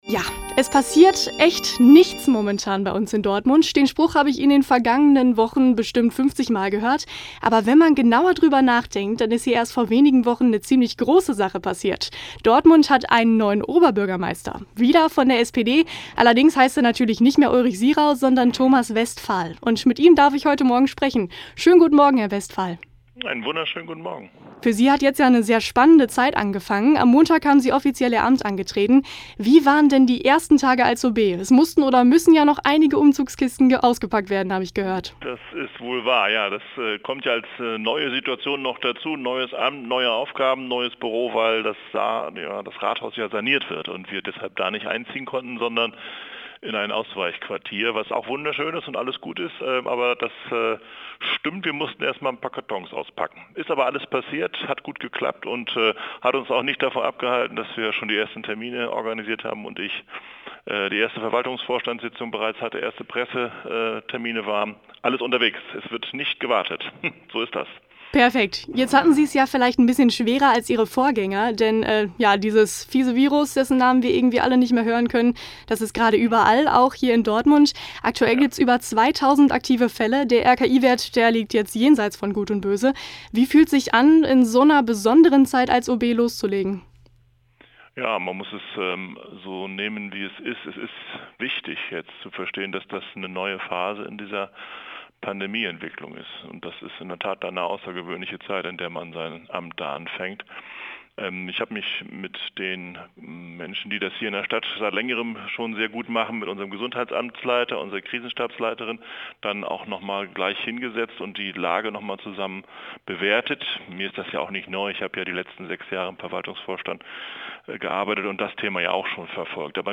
Der neue Dortmunder Oberbürgermeister Thomas Westphal erzählt im eldoradio*-Interview von seinem Start ins neue Amt und wie er Dortmund für Studierende attraktiver machen will. Er spricht über die Verlängerung der H-Bahn, die Verbesserung des Dortmunder Nachtlebens nach Corona und den Ausbau von WG-tauglichen Wohnungen. Warum Dortmund für ihn eine heimliche Studierendenstadt ist, hört ihr im Live-Interview von Morgen des 05. Novembers.